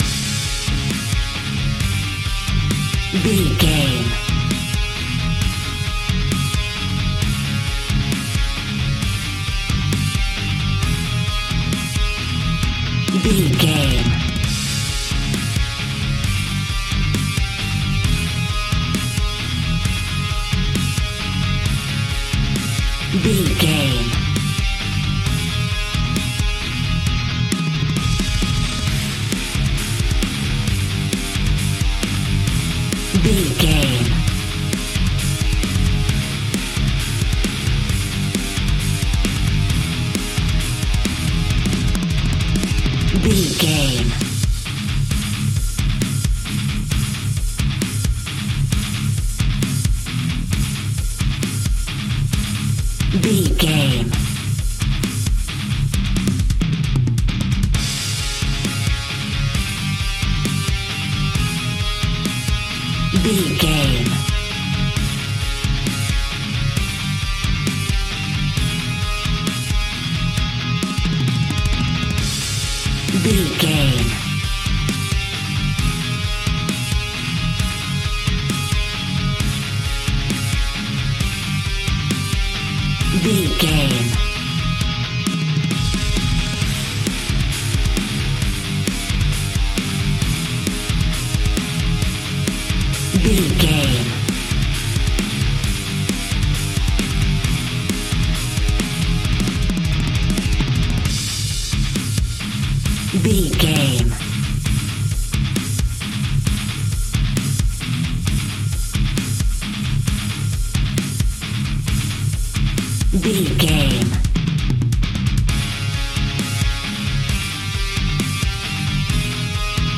Aeolian/Minor
C♯
aggressive
energetic
heavy
ominous
bass guitar
electric guitar
drums